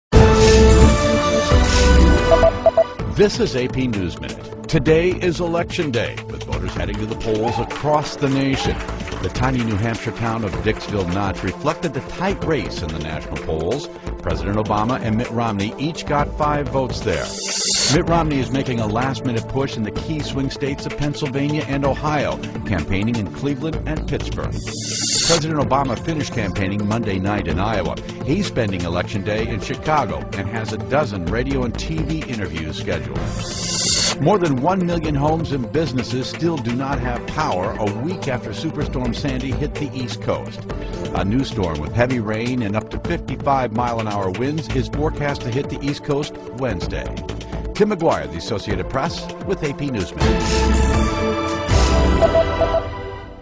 在线英语听力室美联社新闻一分钟 AP 2012-11-08的听力文件下载,美联社新闻一分钟2012,英语听力,英语新闻,英语MP3 由美联社编辑的一分钟国际电视新闻，报道每天发生的重大国际事件。电视新闻片长一分钟，一般包括五个小段，简明扼要，语言规范，便于大家快速了解世界大事。